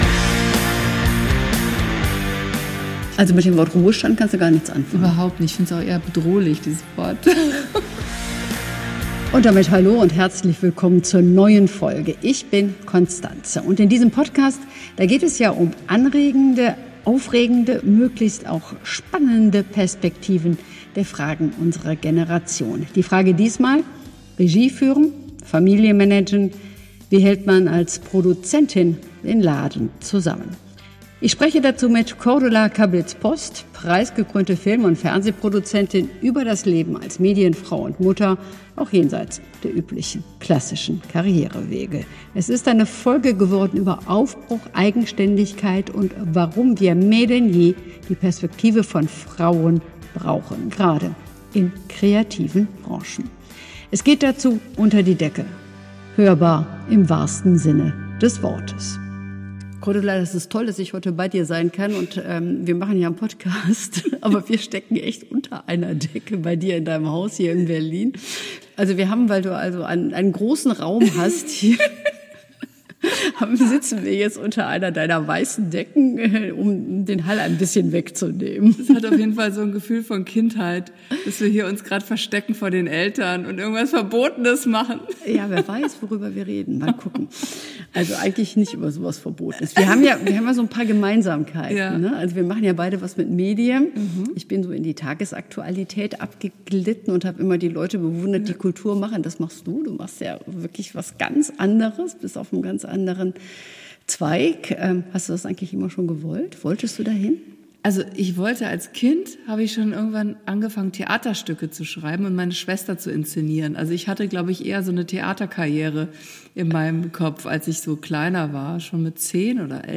Ein Gespräch über ein Leben im Takt der eigenen Ideen – mit Haltung, Witz und sehr viel Energie